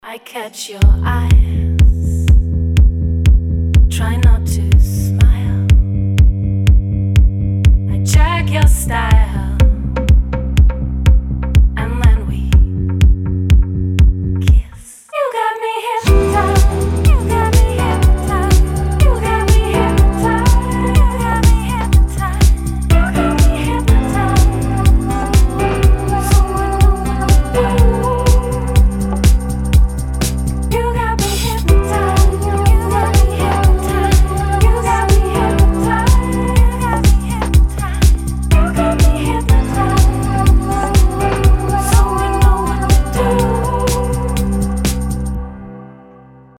• Качество: 320, Stereo
гитара
deep house
мелодичные
детский голос
Интересная музыка в стиле deep house